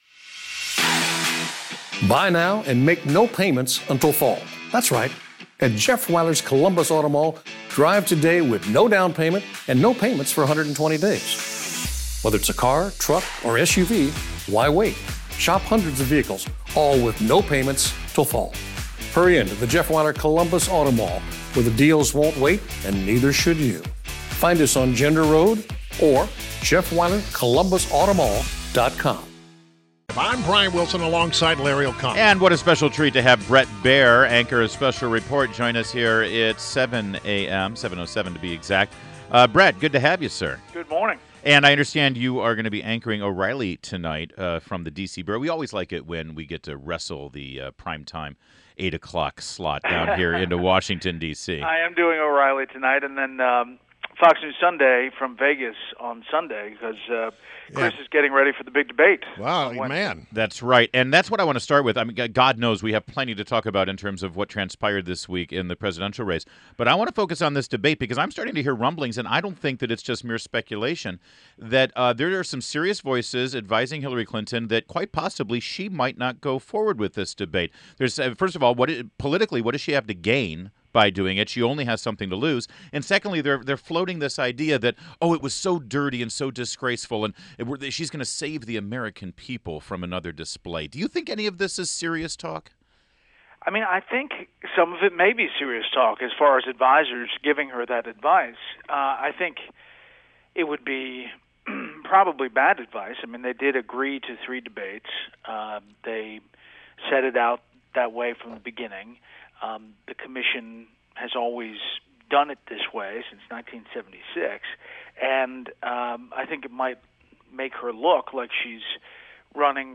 WMAL Interview - BRET BAIER - 10.14.16
INTERVIEW – BRET BAIER – Anchor of Special Report weekdays at 6 pm and on Sundays at 8 pm on Fox News Channel